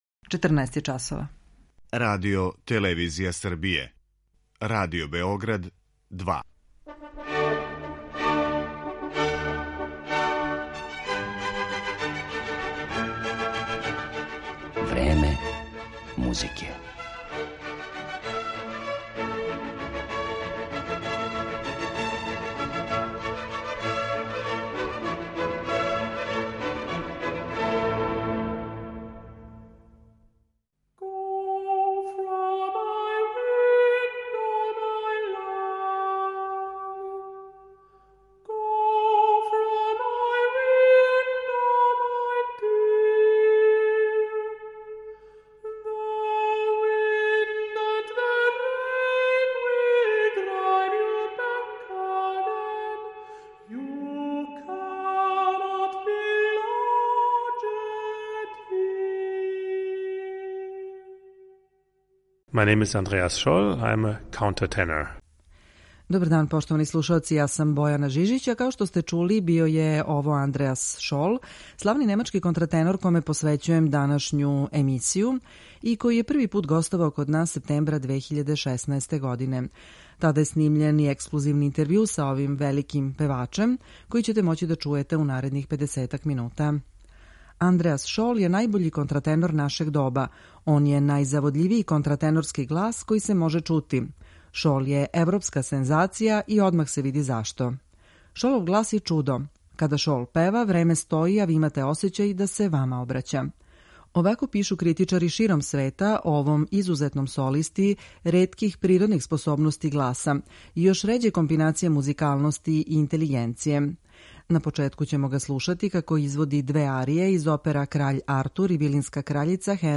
Као најбољег контратенора света и најзаводљивији контратенорски глас који се мозе чути, описују критичари широм света славног немачког певача Андреаса Шола коме је посвећена данашња емисија.
У њој ћете моћи да слушате и екслузивни интервју снимљен са овим славним уметником приликом његовог првог гостовања у Београду, септембра 2016. године.